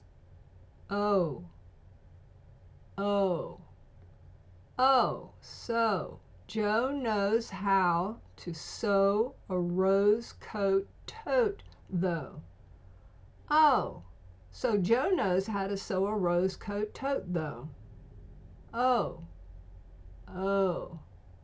American Vowel Long O
Long ō vowel diphthong
LongO.m4a